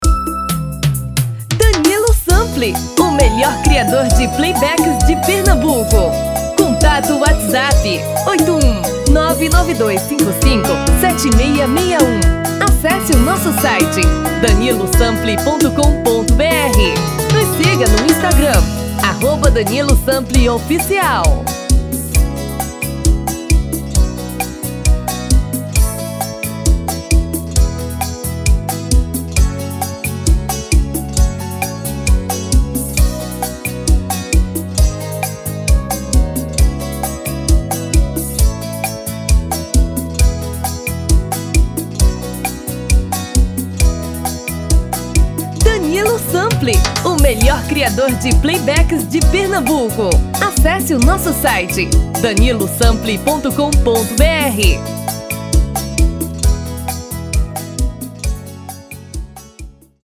RITMO: Arrocha / Seresta
TOM: Feminino (Original)